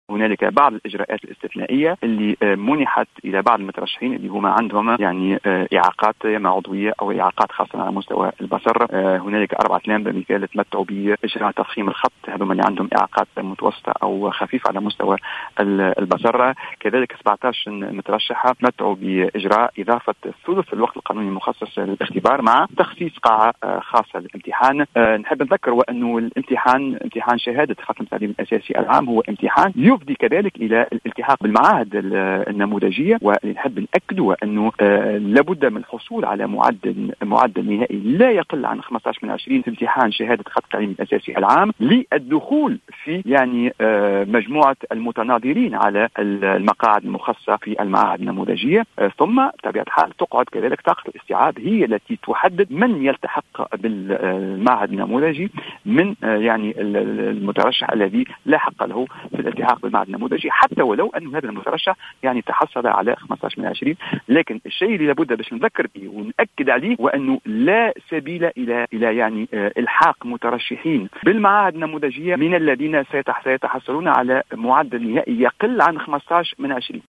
قال المدير العام للامتحانات، عمر الولباني، في تصريح ل"الجوهرة أف أم" اليوم الأحد 23 جوان 2019 أنه تم اتحاذ بعض الإجراءات الإستثنائية لفائدة بعض المترشحين لمناظرة النوفيام التي تنطلق يوم غد الإثنين.